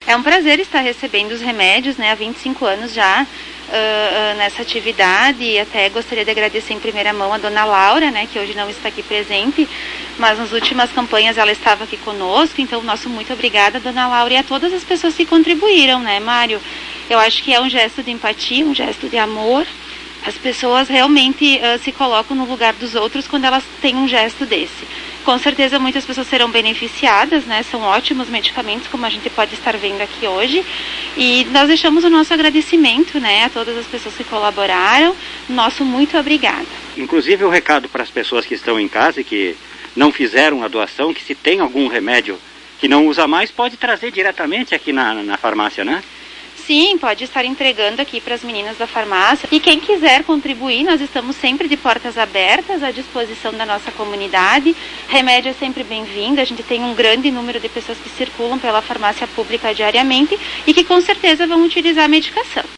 Ouça manifestação da secretária.